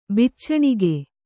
♪ biccaṇige